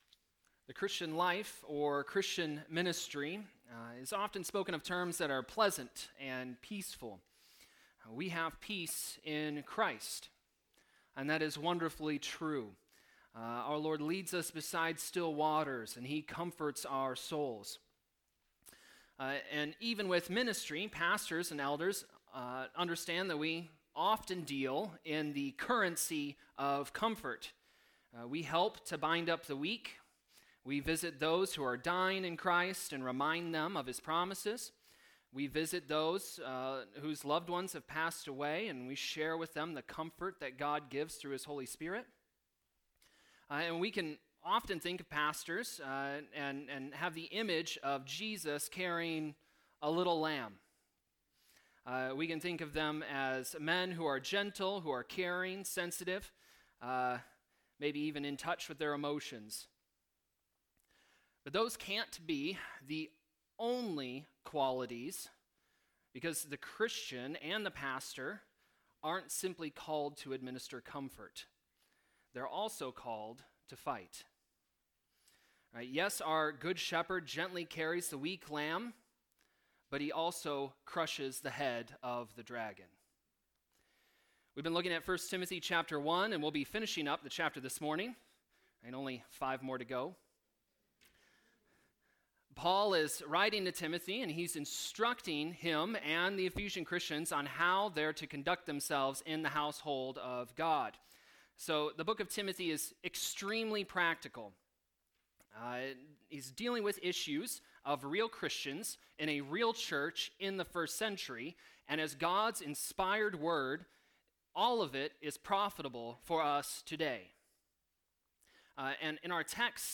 Sermon preached Sunday, March 1, 2020